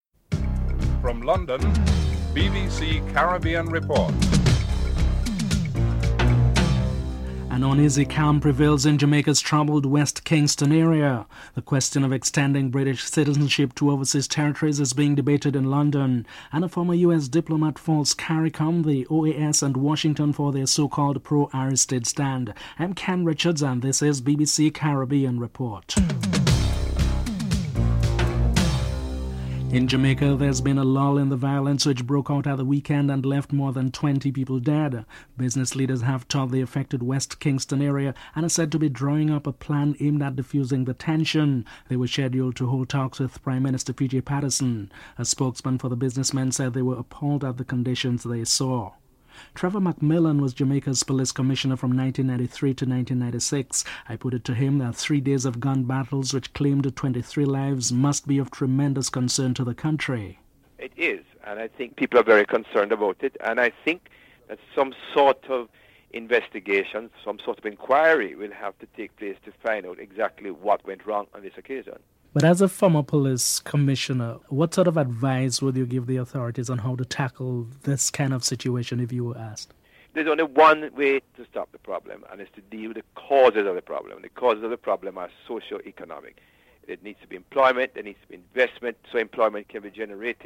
1. Headlines (00:00-00:28)
Former US Ambassador to Haiti Ernest Preeg is interviewed (09:19-12:14)
Caricom outgoing Chief Negotiator Sir Shridath Ramphal is interviewed (14:25-15:38)